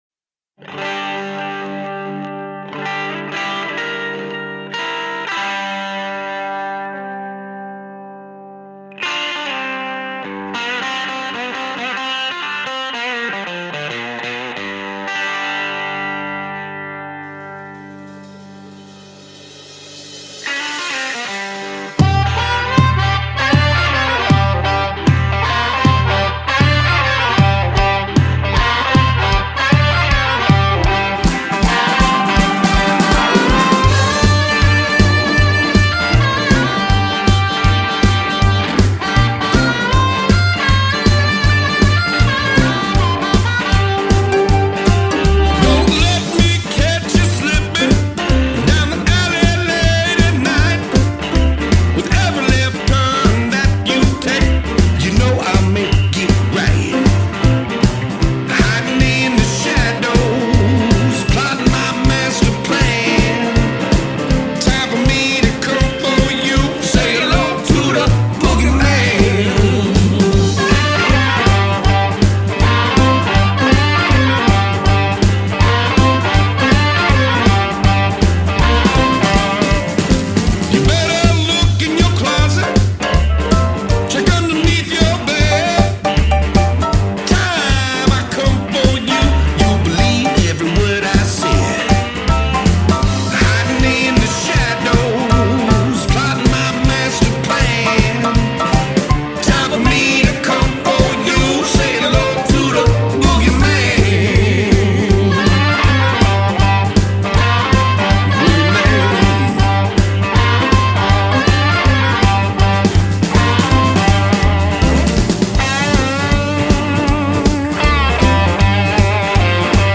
Blues/roots supergroup